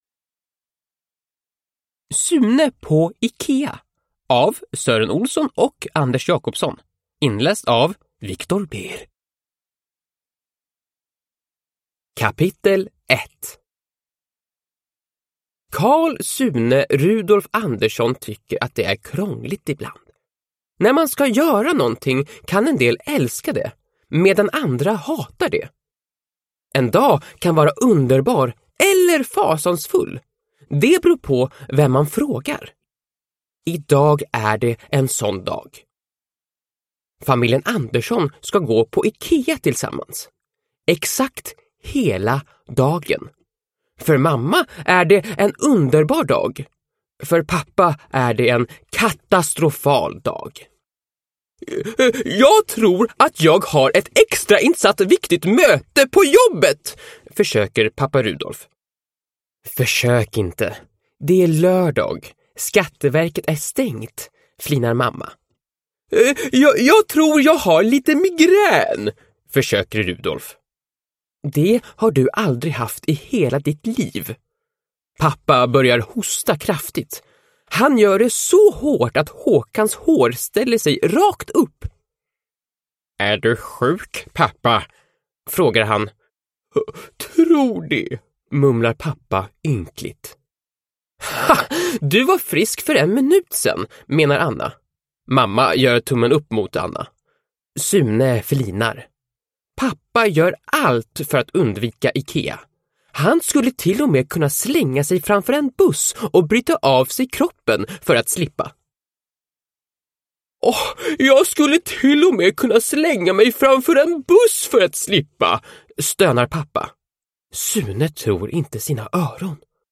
Sune på Ikea – Ljudbok